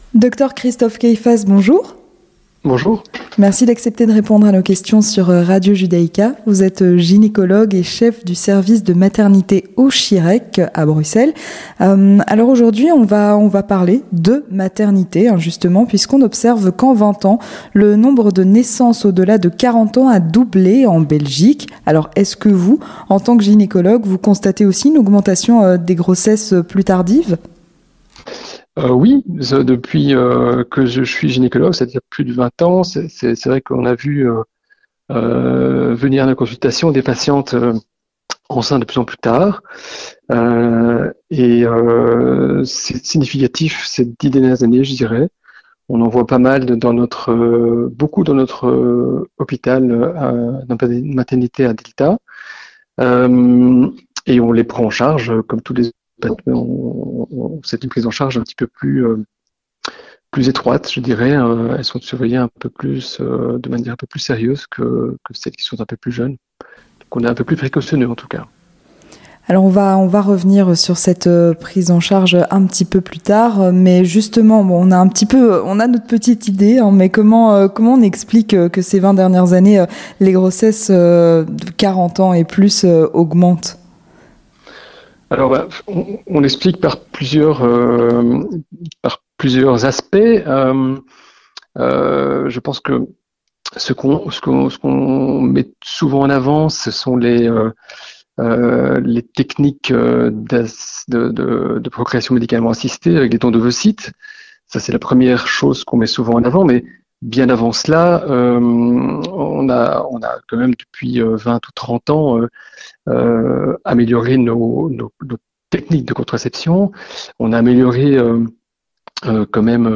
Présenté par